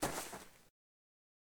PixelPerfectionCE/assets/minecraft/sounds/mob/rabbit/hop2.ogg at mc116